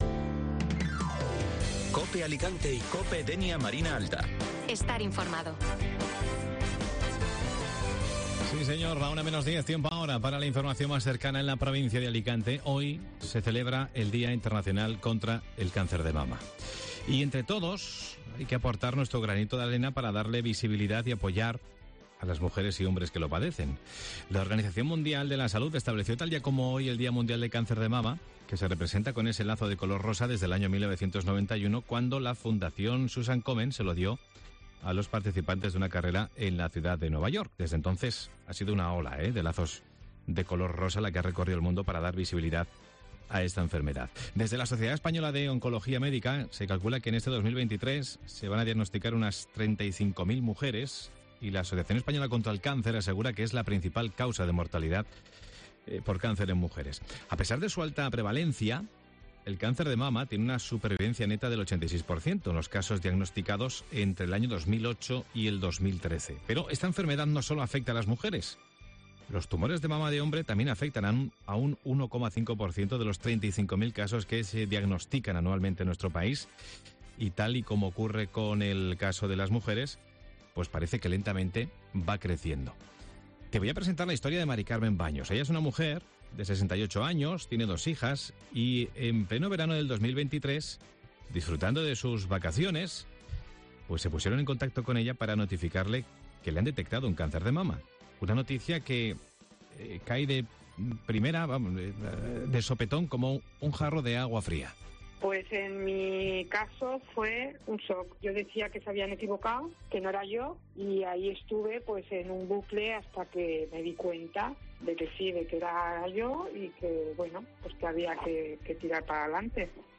Espacio magazine que se emite de lunes a viernes de 12:50h a 13:00h y de 13:50h a 14:00h con entrevistas y actualidad de la provincia de Alicante.